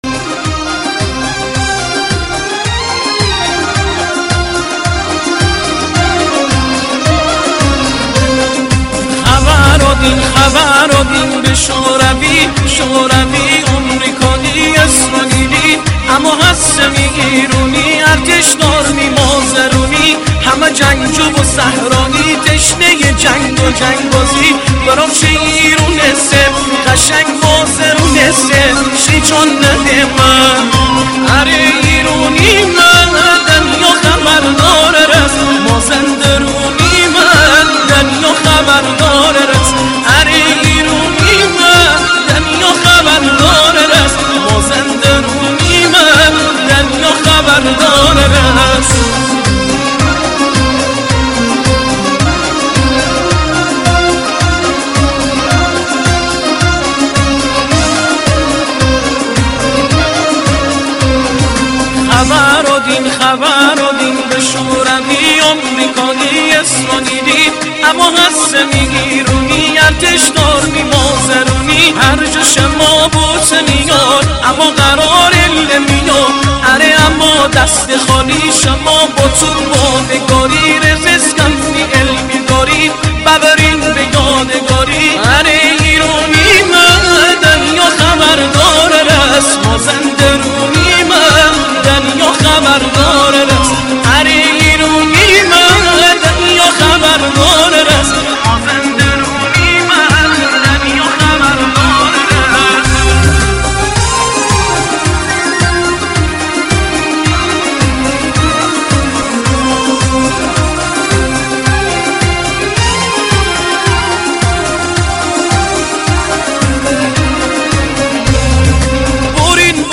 آهنگ شاد شمالی برای عروسی